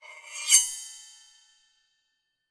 描述：用刀刮一块金属片。由RØDEM3修改。
Tag: 金属